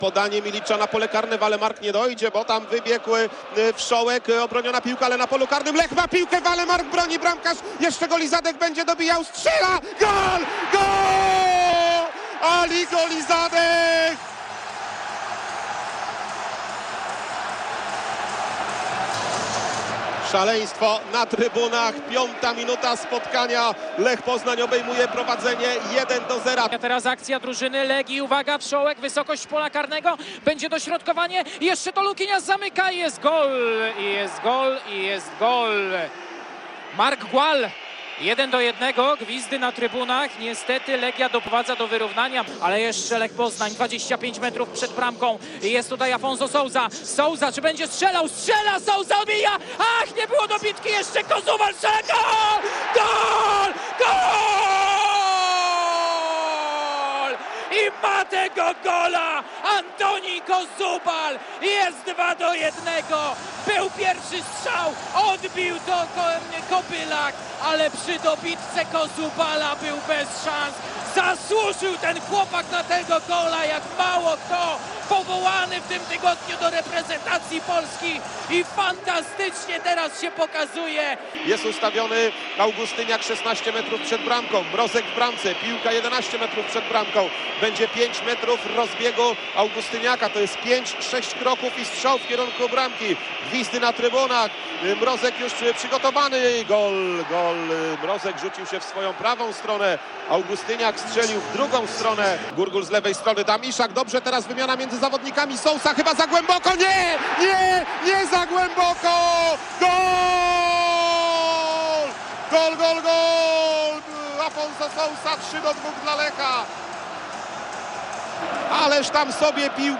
oihuw8dfi143fe3_gole-lech-legia.mp3